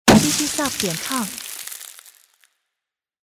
糖果枪五彩纸屑炮/枪与纸碎片散射
高品质的声音五彩纸屑炮/枪与纸碎片散射。
采样率:16位立体声，44.1 kHz